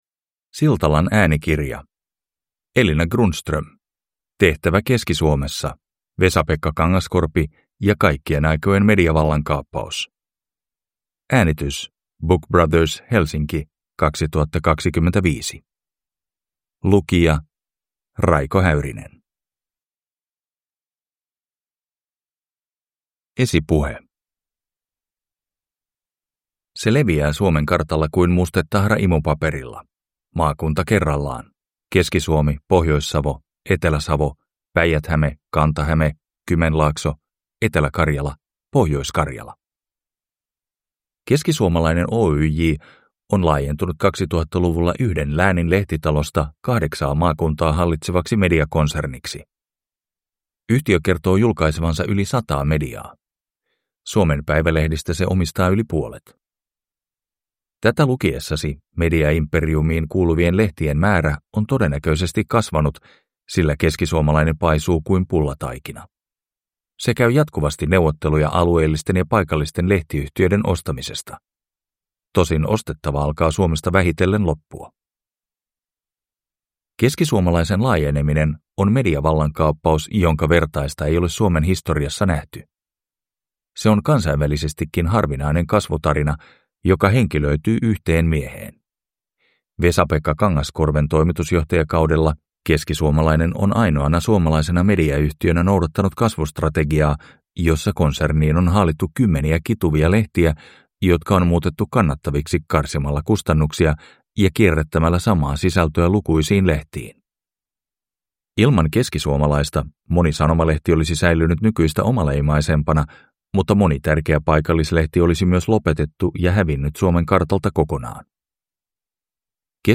Tehtävä Keski-Suomessa – Ljudbok